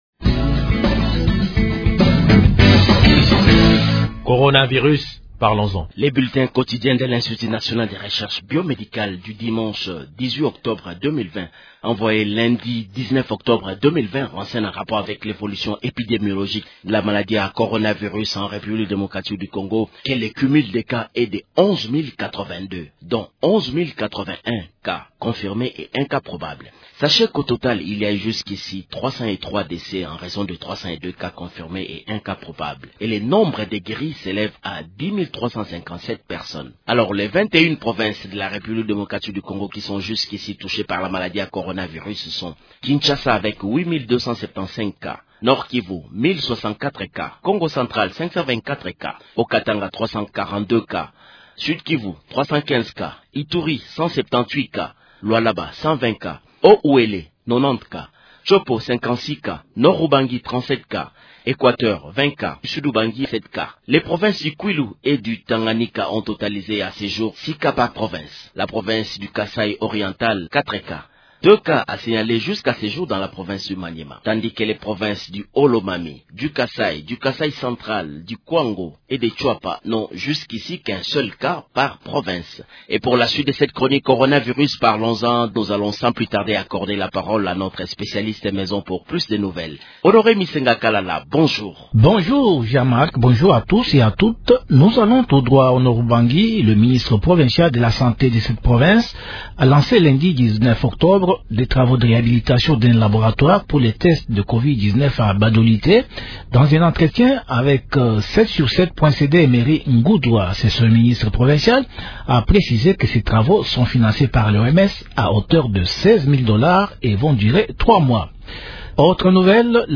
Actualité